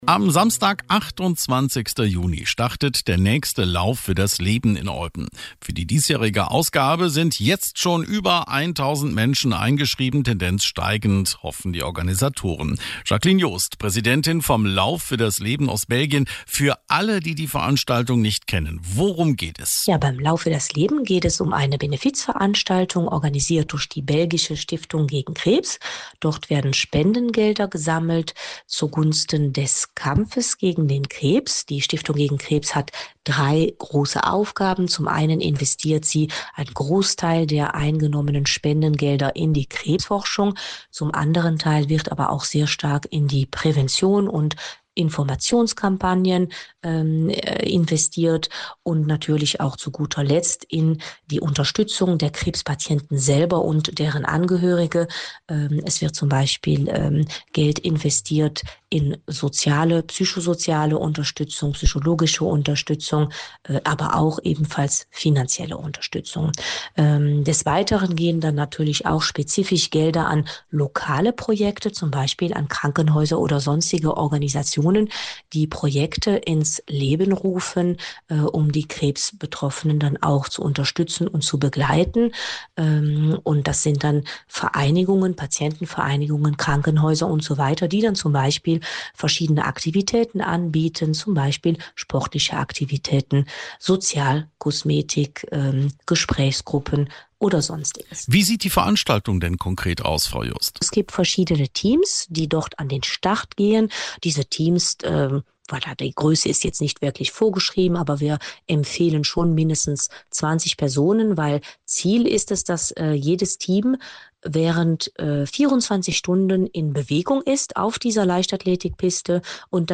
Lauf-für-das-Leben-2025-WebInterview.mp3